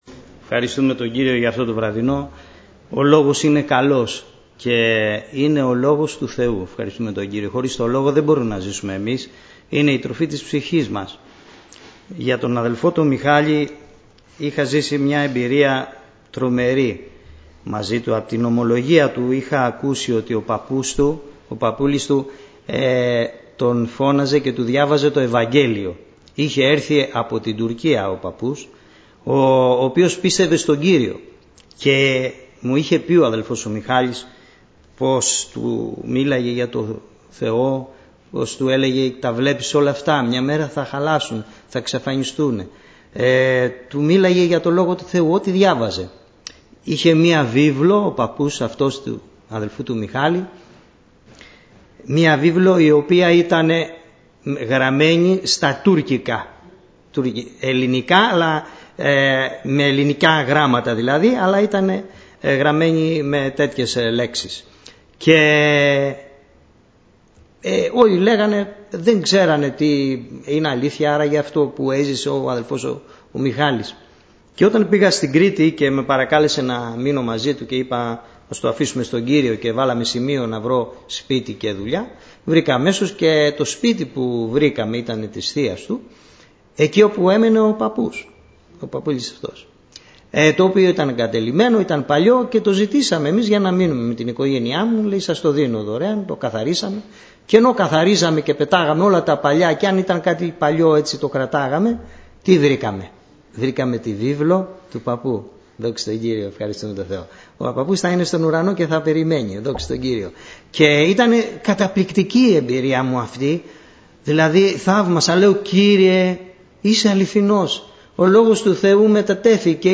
Κηρύγματα